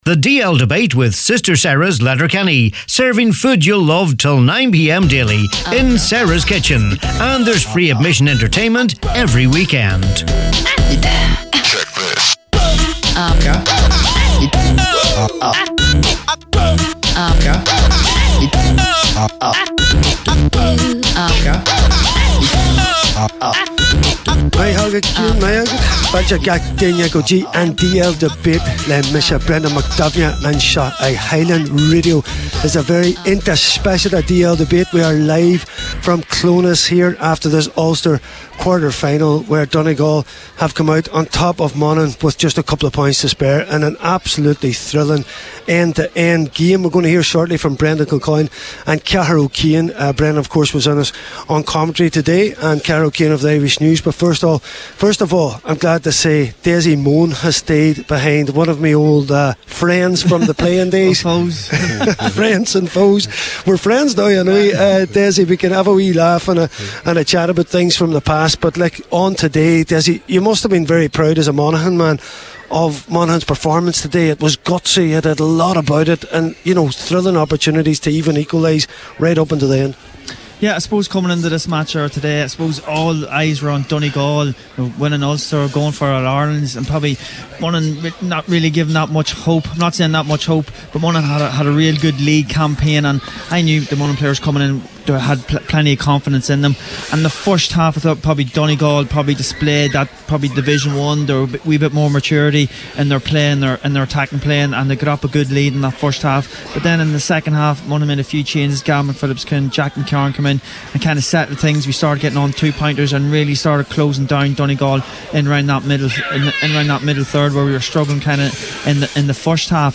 This week’s DL Debate broadcasts live from St. Tiernach’s Park in Clones after Donegal’s two-point win over Monaghan in the Ulster Senior Football Championship Quarter Final.